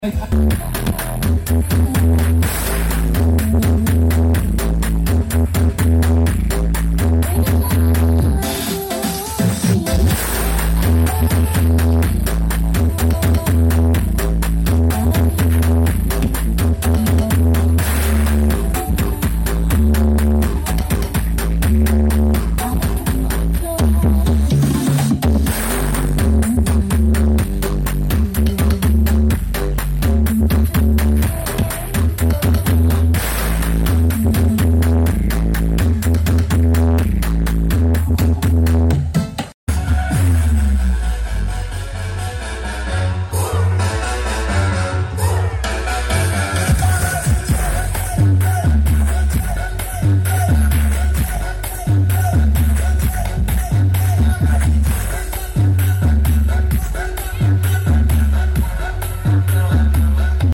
Ultima Audio Blitar Karnaval Bangorejo#ultimaaudioblitar Sound Effects Free Download